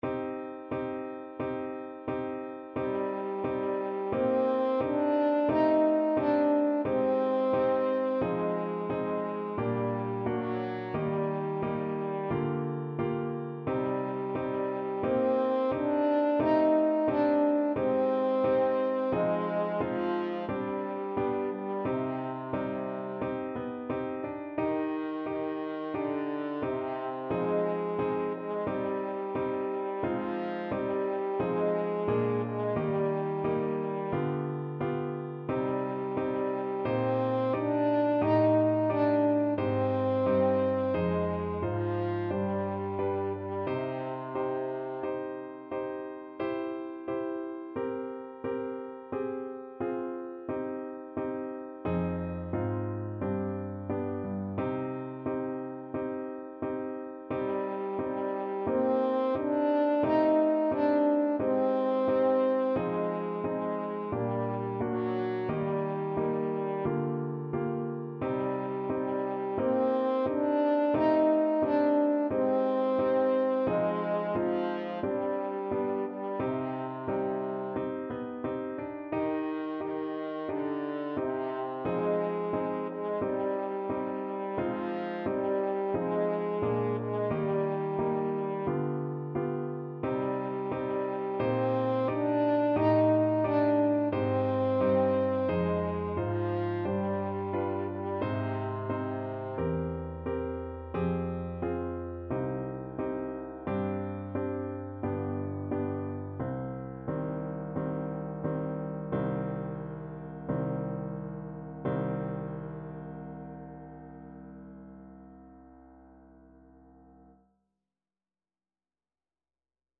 French Horn
4/4 (View more 4/4 Music)
Andante =c.88
C minor (Sounding Pitch) G minor (French Horn in F) (View more C minor Music for French Horn )
Classical (View more Classical French Horn Music)